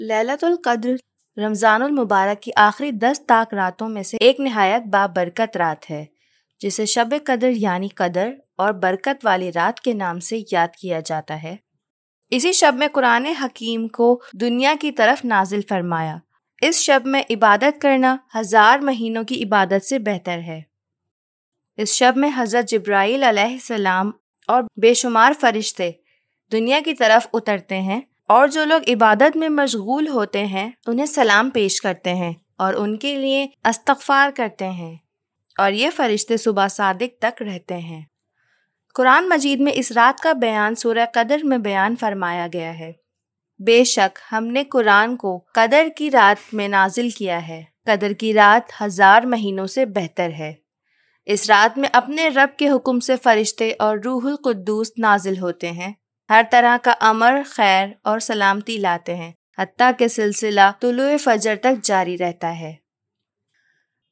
Urduca Seslendirme
Kadın Ses